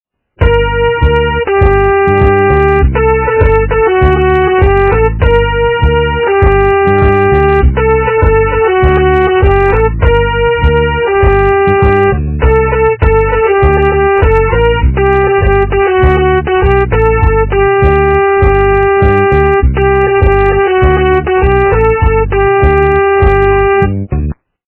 - русская эстрада
качество понижено и присутствуют гудки.